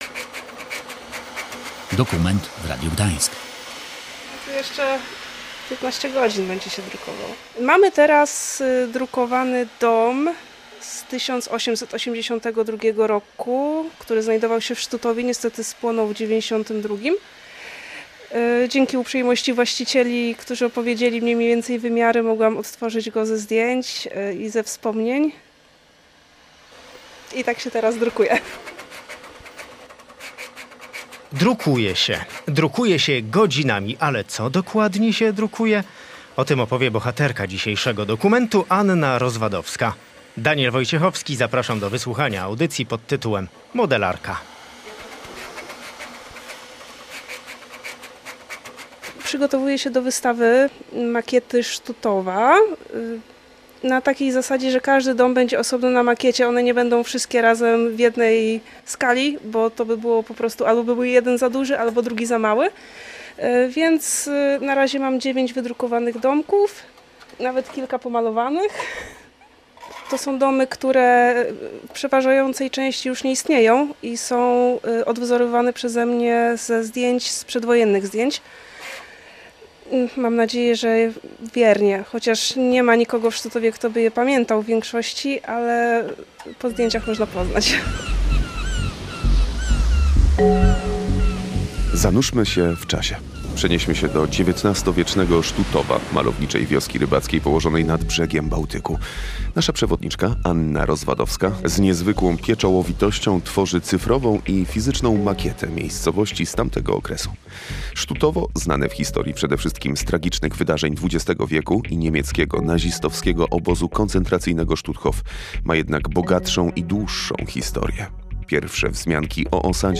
Dokument „Modelarka” – opowieść o XIX-wiecznym Sztutowie, który wraca w miniaturze